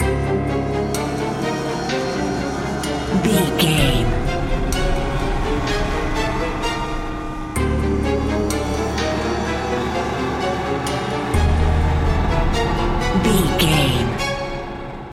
Atonal
drone
suspense
eerie
medium tempo